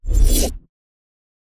cast-generic-02.ogg